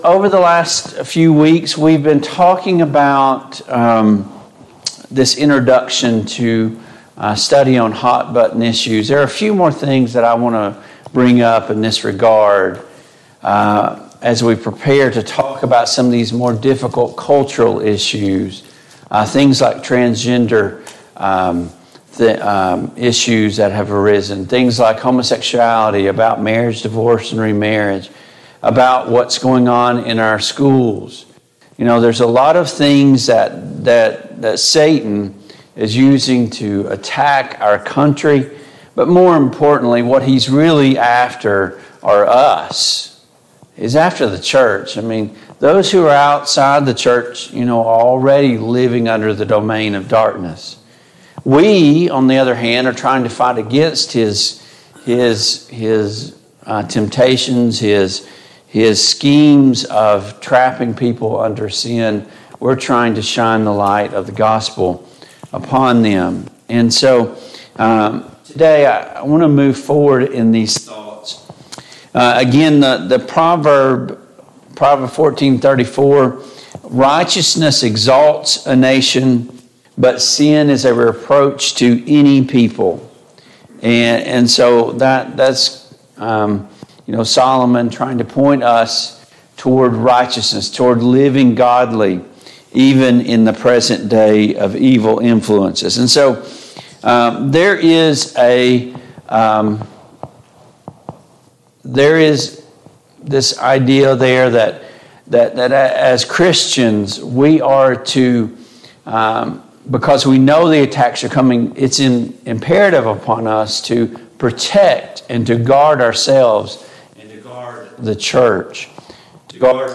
Service Type: Sunday Morning Bible Class Topics: False Doctrine , Sin and Temptation